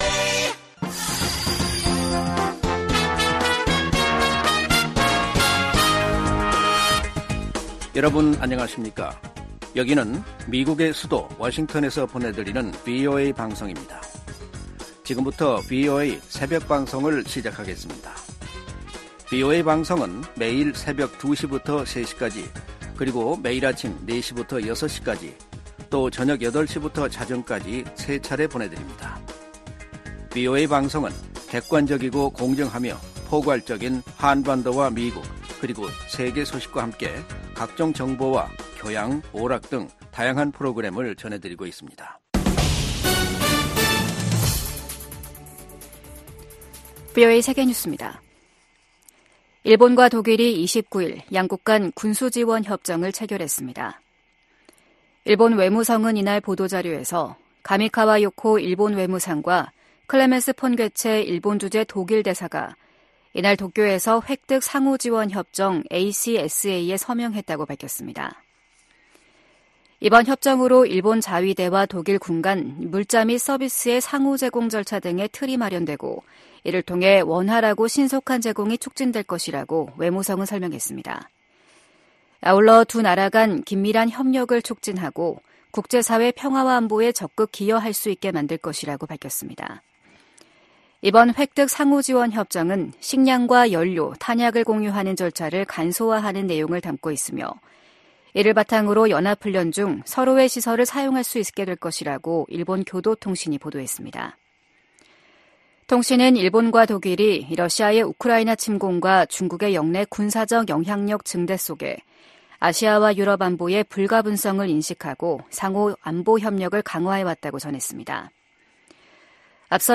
VOA 한국어 '출발 뉴스 쇼', 2024년 1월 30일 방송입니다. 북한은 어제 시험발사한 미사일이 새로 개발한 잠수함발사 순항미사일이라고 밝혔습니다.